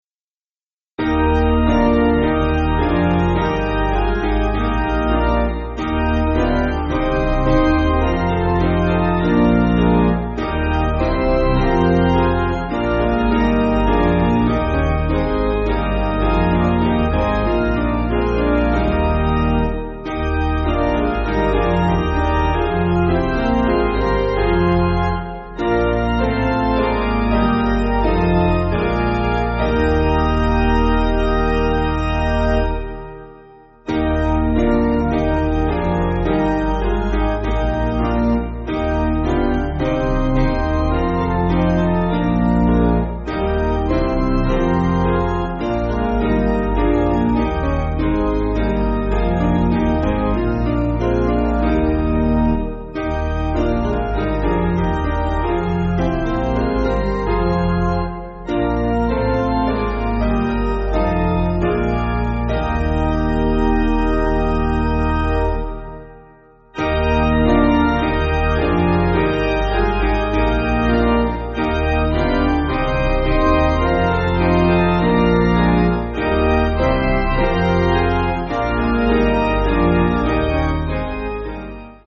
Basic Piano & Organ
(CM)   3/Eb